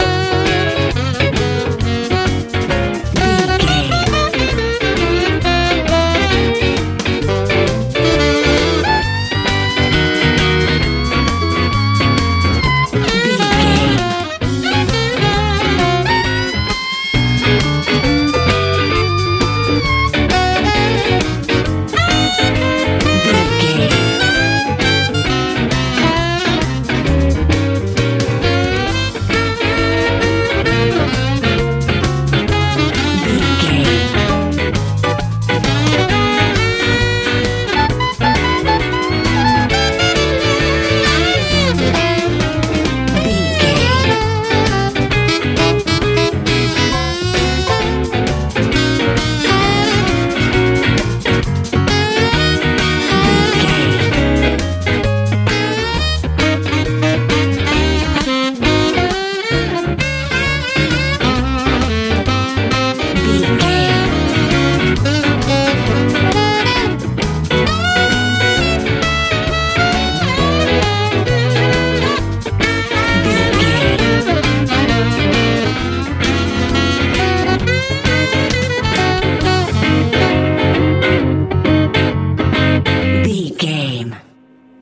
blues rock feel
Ionian/Major
Fast
groovy
funky
saxophone
piano
electric guitar
bass guitar
drums
powerful
playful
sexy